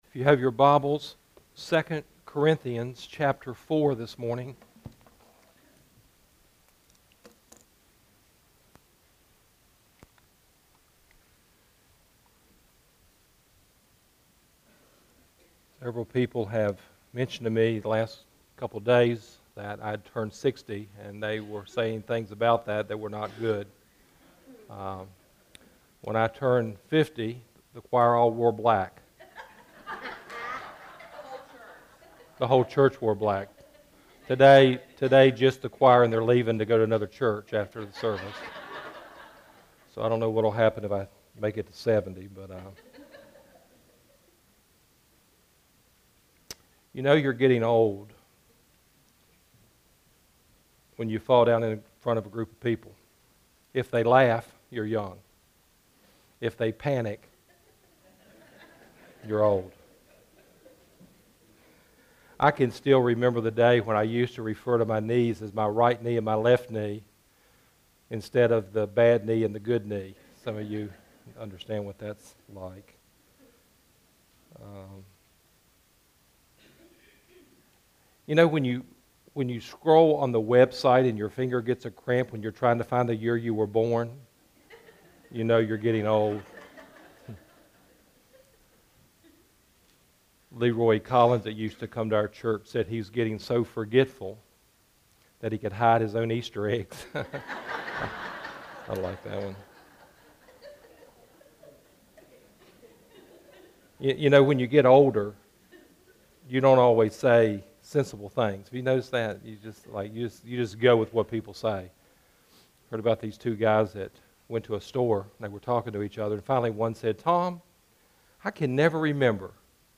Sermon Archive – Page 4 – Goodview Baptist Church